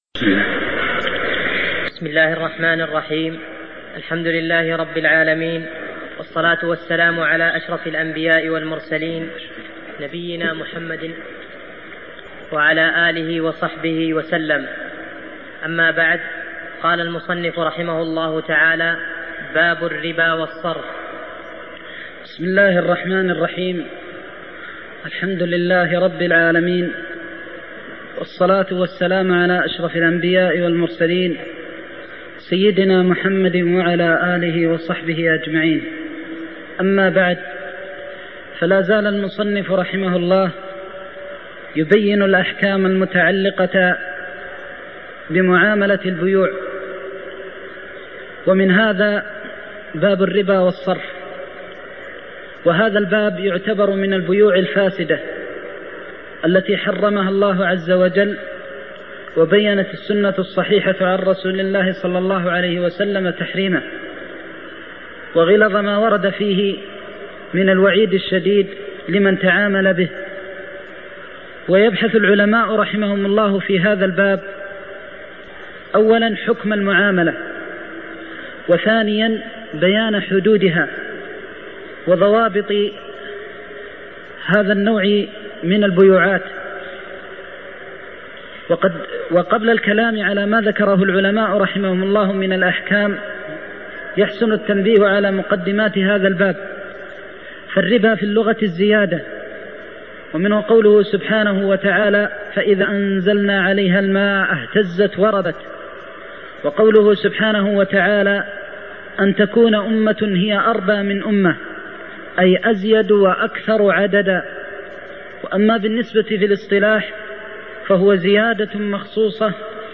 تاريخ النشر ٥ ربيع الأول ١٤١٧ هـ المكان: المسجد النبوي الشيخ